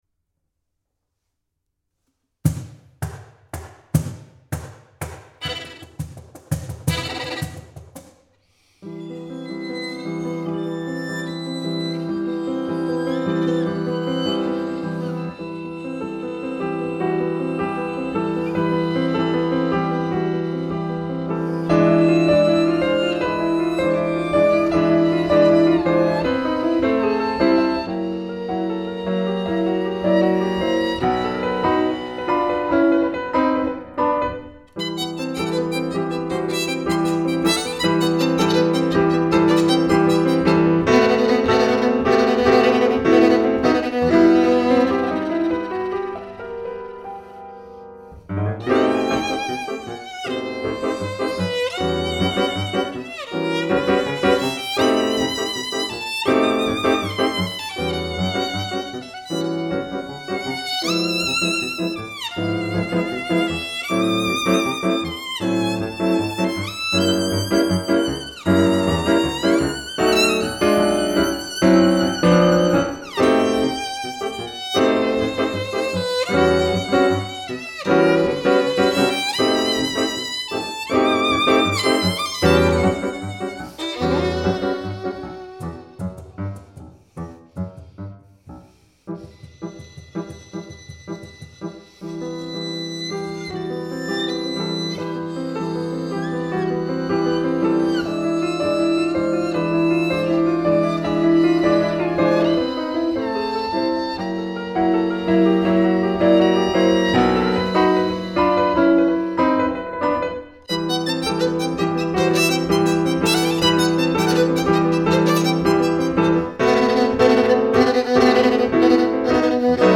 Duo à dominante TANGO